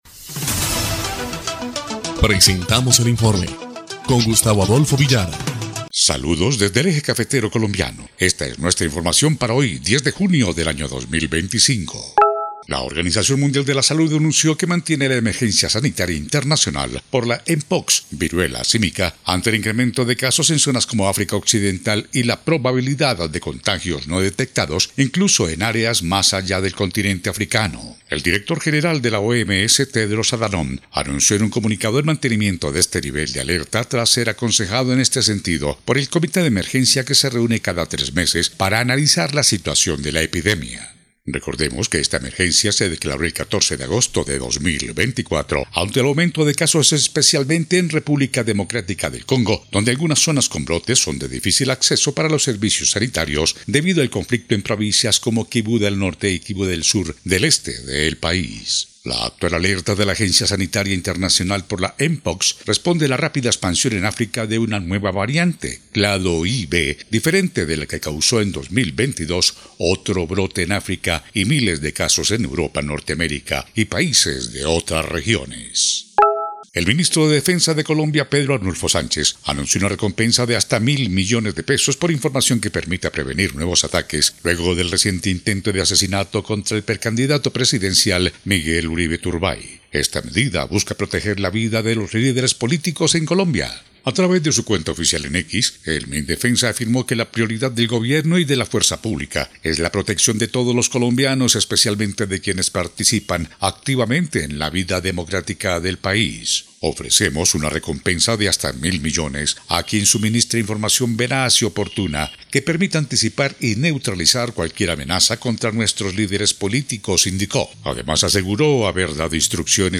EL INFORME 3° Clip de Noticias del 10 de junio de 2025